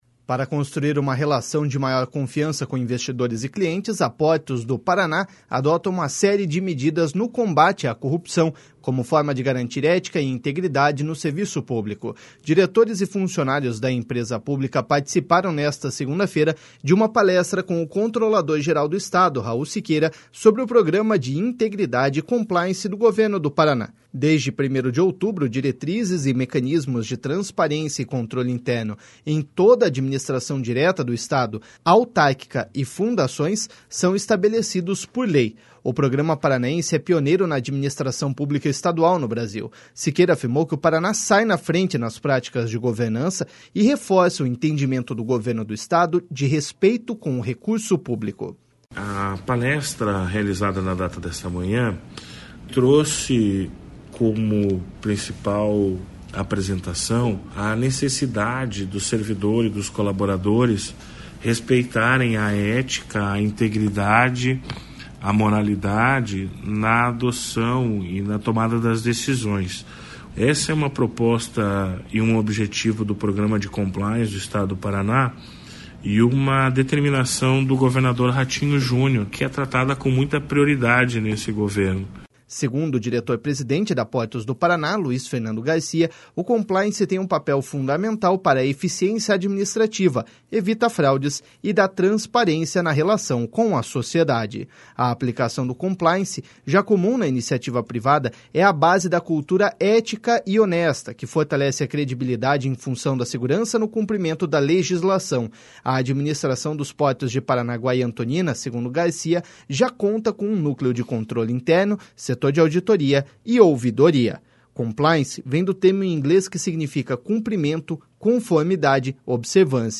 Siqueira afirmou que o Paraná sai na frente nas práticas de governança e reforça o entendimento do Governo do Estado de respeito com o recurso público.// SONORA RAUL SIQUEIRA.//